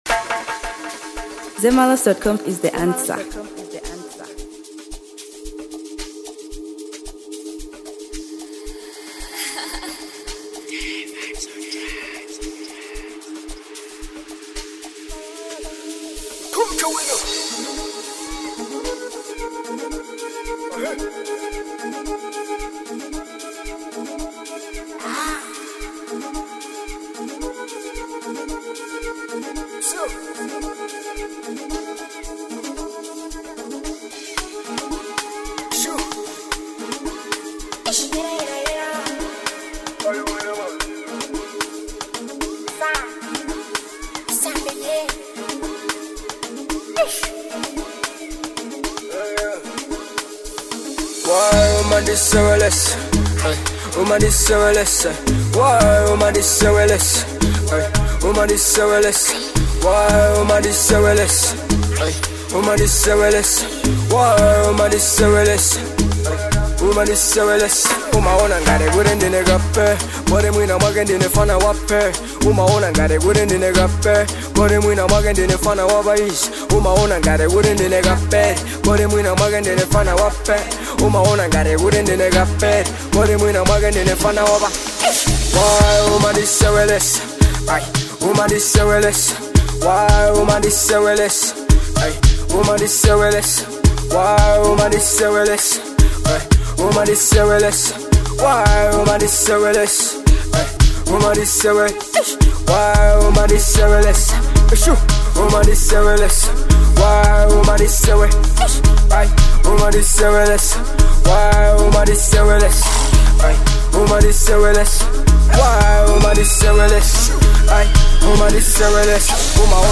Genre: Amapiano.